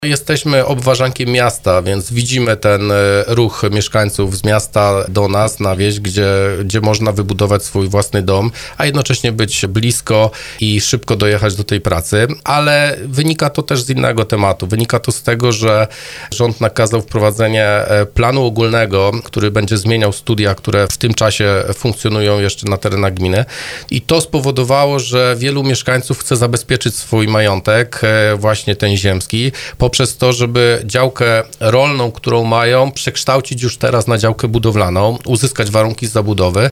Zastępca wójta Grzegorz Skrzypek przyznał w audycji Słowo za Słowo, że gmina Dębica jest bardzo dobrym miejscem do zapuszczenia korzeni, to jednak podejrzewa, że ten trend mógł mieć inne podłoże.